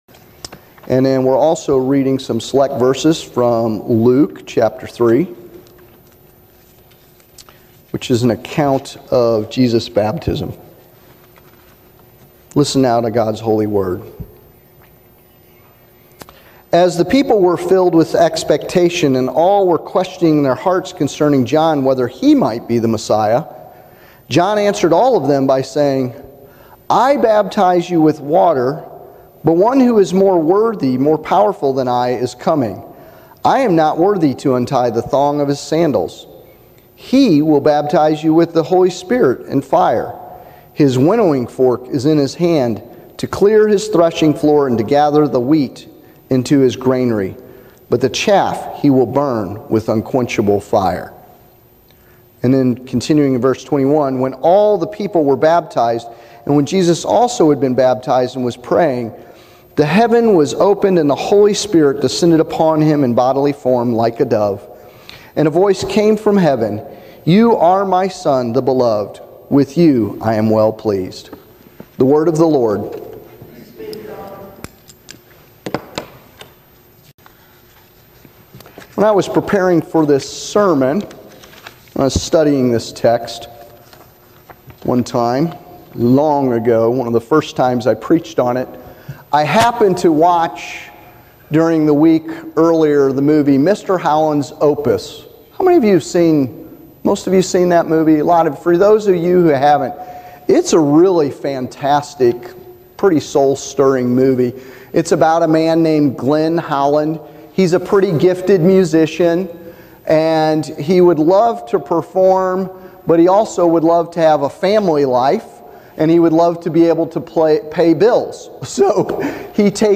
This sermon is based on Luke’s account of the baptism of Jesus, Luke 3:15-17, 21-22.